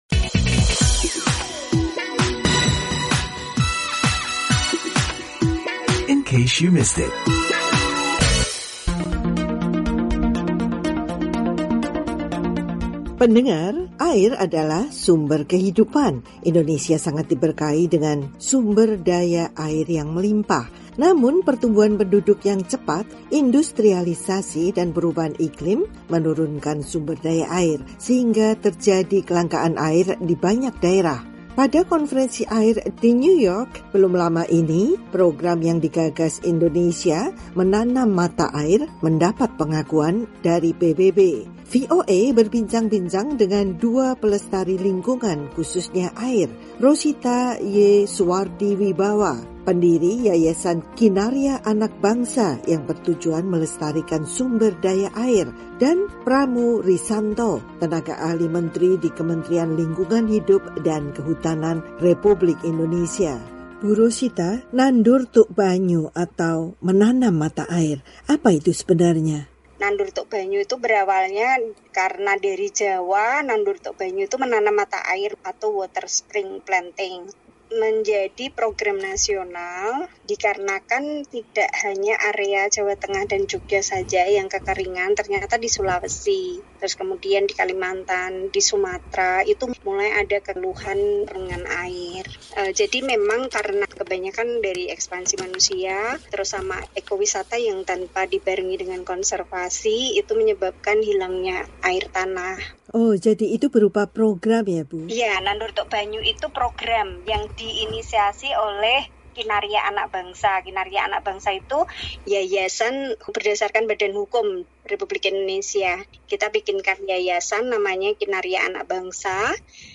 berbincang dengan dua pelestari lingkungan